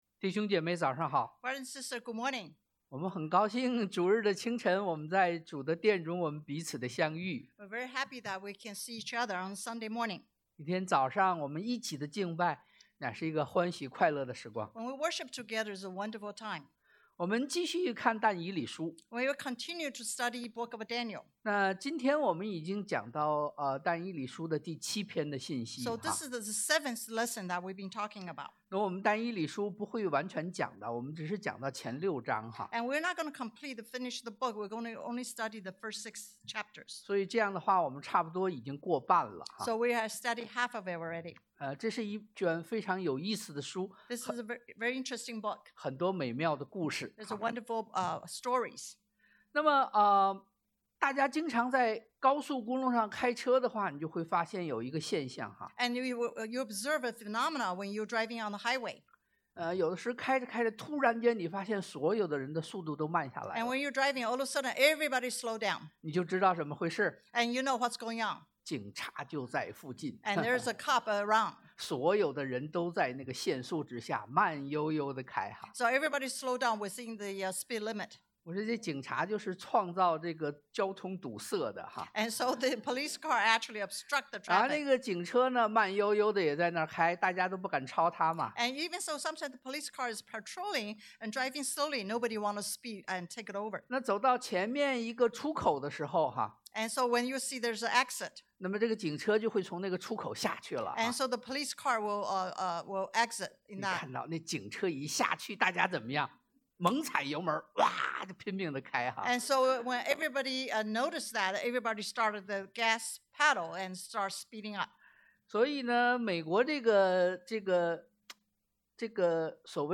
但 Dan 4:1-18 Service Type: Sunday AM 1.